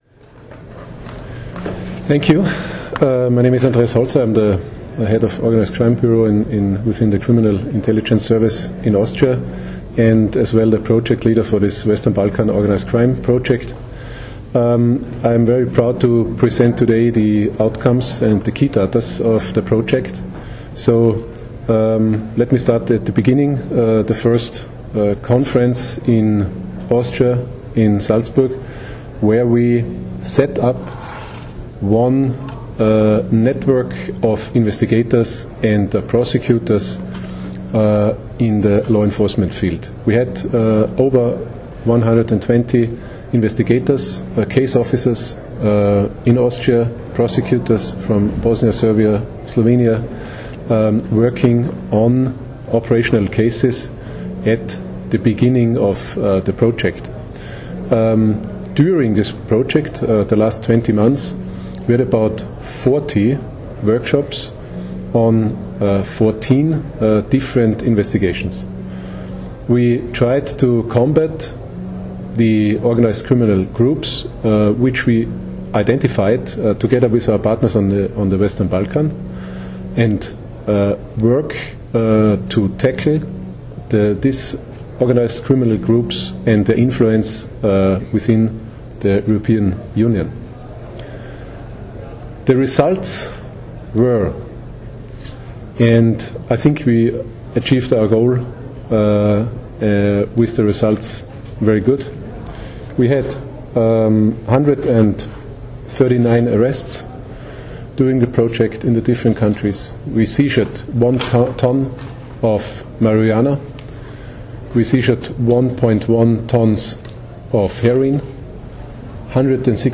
V Grand Hotelu Bernardin v Portorožu se je danes, 10. junija 2015, začela dvodnevna zaključna konferenca projekta WBOC - skupne preiskave z državami zahodnega Balkana v boju zoper organiziran kriminal in učinki na EU.
Zvočni posnetek govora Andreasa Holzerja, (mp3)
vodje Biroja za organizirano kriminaliteto in vodja projekta WBOC